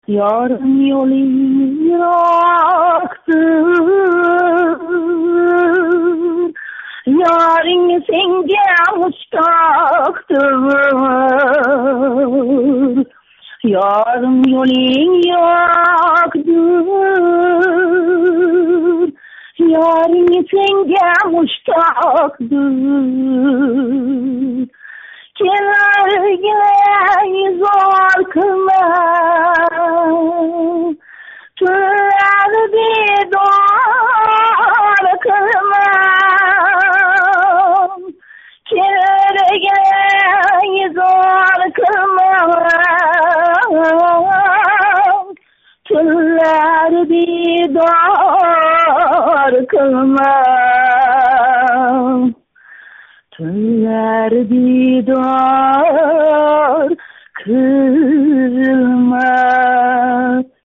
ашуладан бир парча